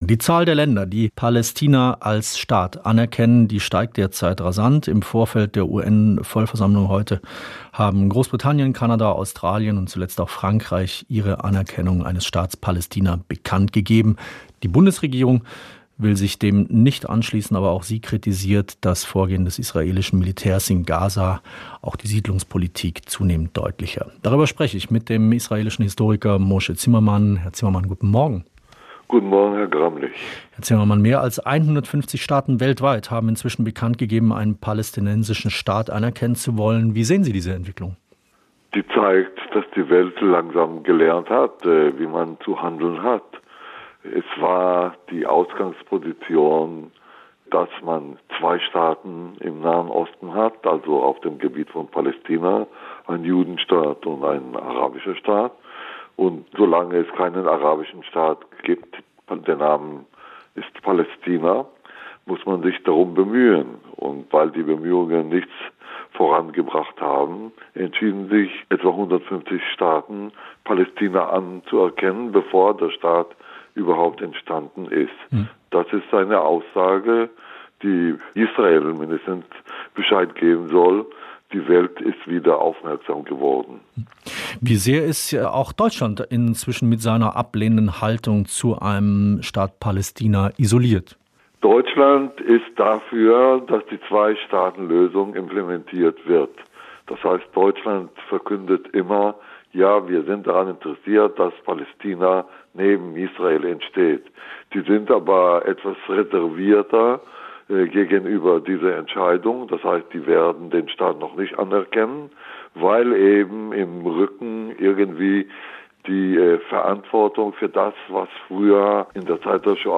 Das Interview führte
Interview mit